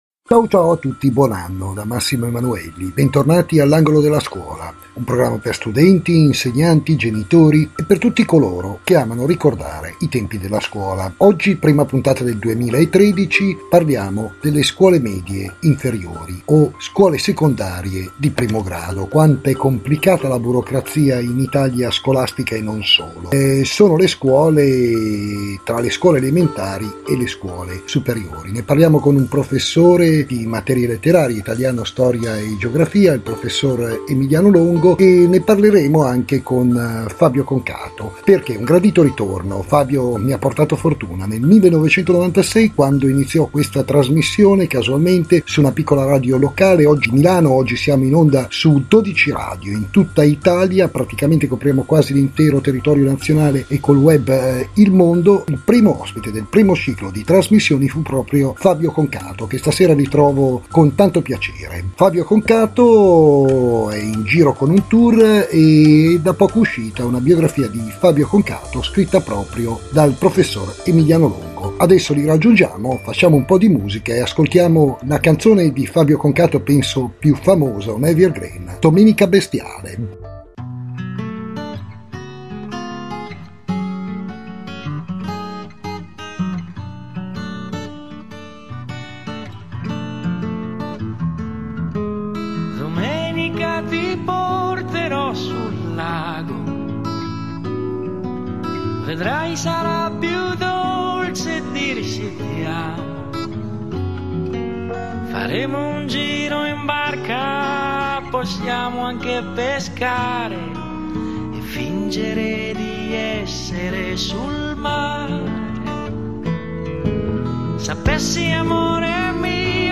Fabio Concato è stato il primo ospite della prima puntata della trasmissione radiofonica L’angolo della scuola, la prima ed unica trasmissione radiofonica interamente dedicata al mondo della scuola, iniziata nel settembre 1990 su una radio locale milanese (primo ospite proprio Fabio Concato). Concato è tornato ospite nel 2013 con il programma ora in onda su cinque emittenti e che è diventata la trasmissione più ascoltata sul web.